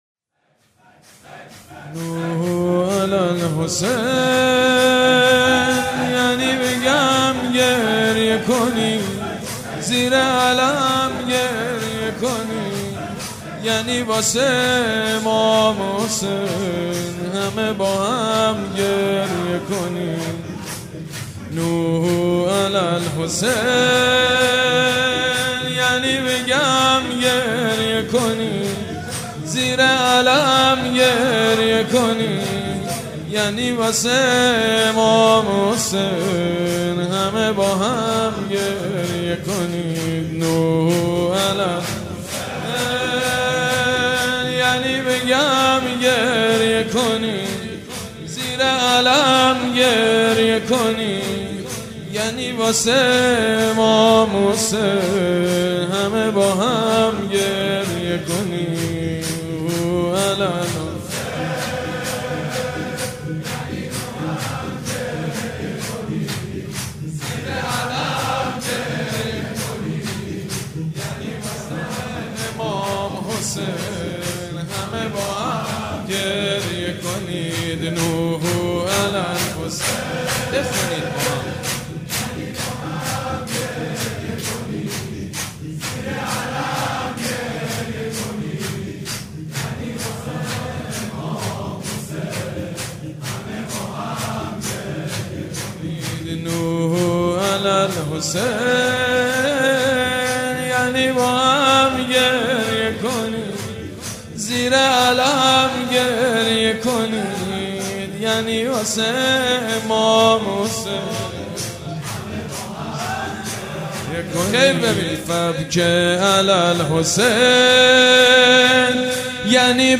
مداحی ویژه ایام مسلمیه